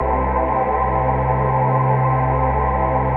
VOICEPAD06-LR.wav